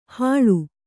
♪ haḷu